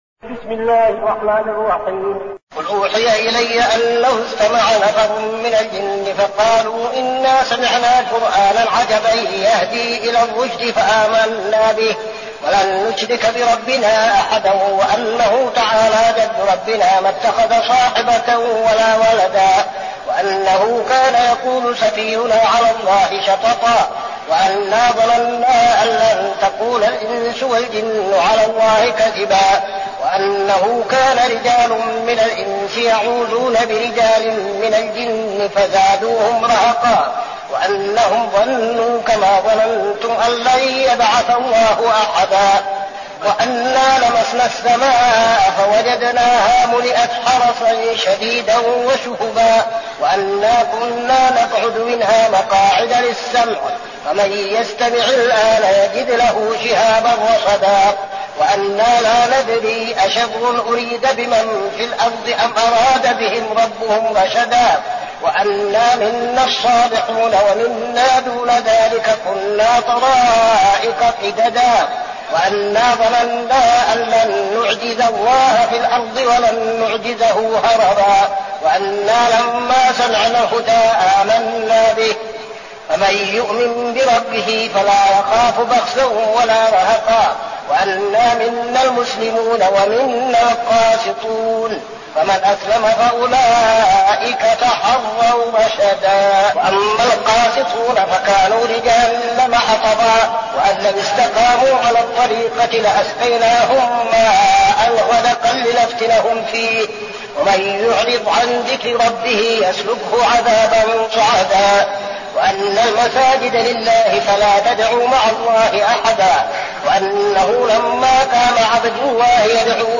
المكان: المسجد النبوي الشيخ: فضيلة الشيخ عبدالعزيز بن صالح فضيلة الشيخ عبدالعزيز بن صالح الجن